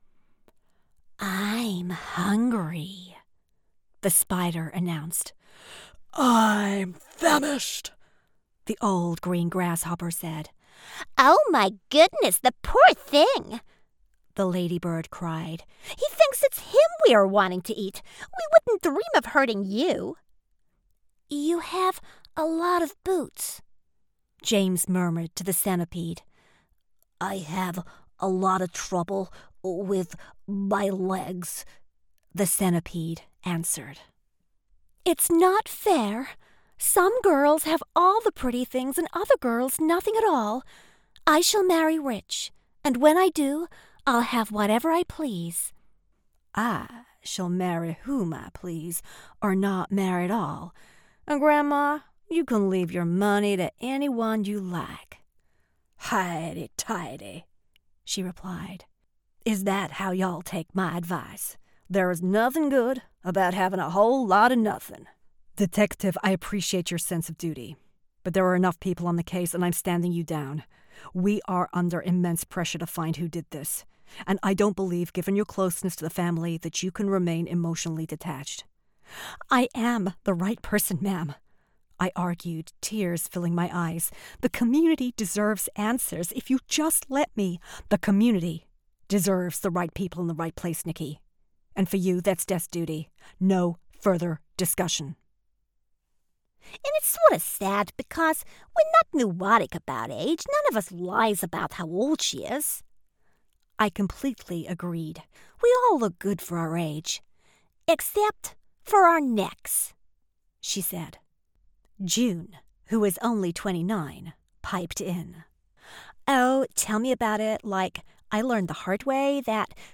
• Home Studio